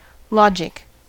logic: Wikimedia Commons US English Pronunciations
En-us-logic.WAV